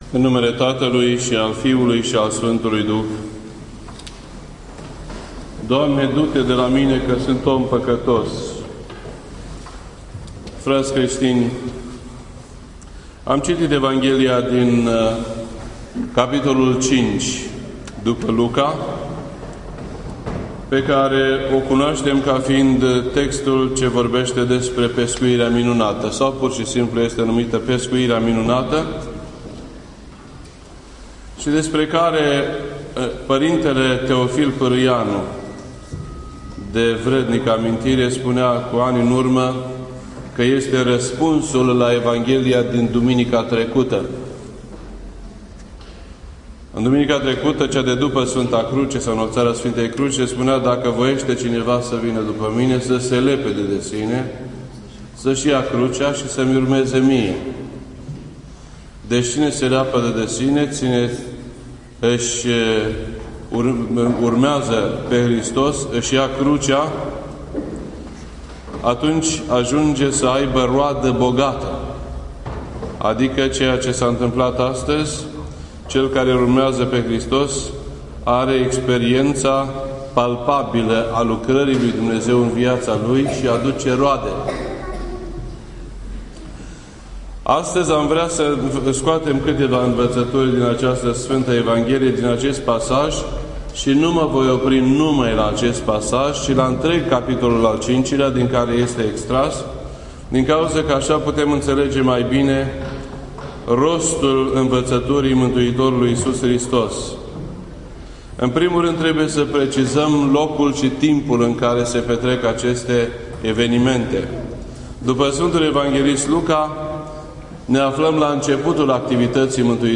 This entry was posted on Sunday, September 27th, 2015 at 11:43 AM and is filed under Predici ortodoxe in format audio.